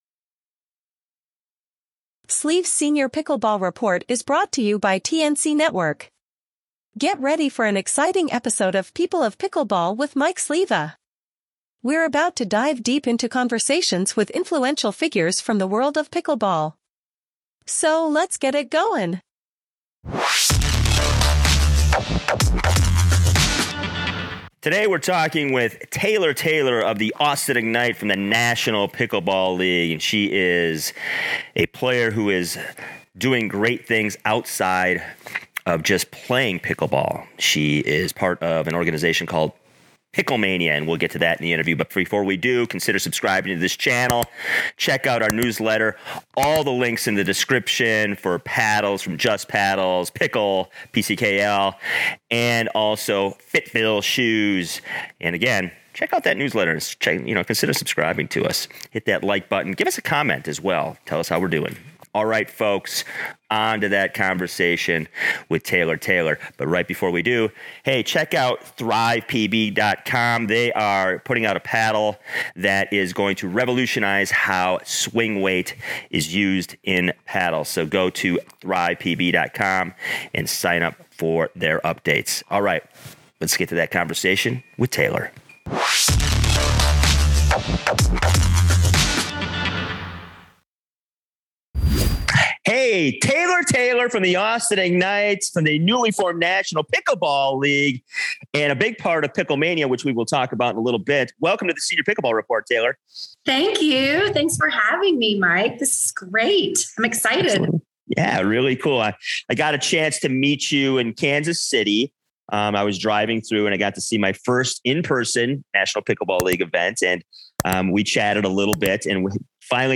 Pickle-Mania-Is-How-Pickleball-Changes-The-World-mixdown.mp3